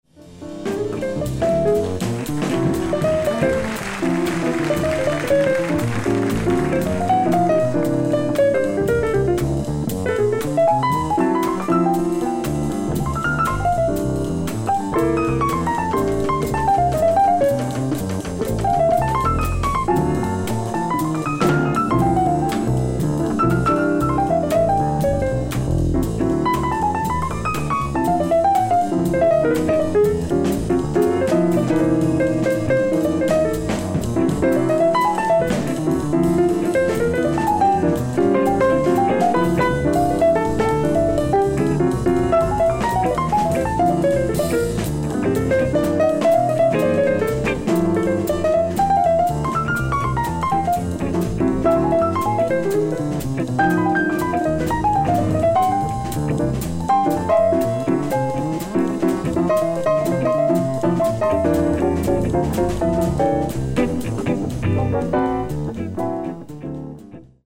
ライブ・アット・モントルー・ジャズフェスティバル、スイス 07/12/1978
※試聴用に実際より音質を落としています。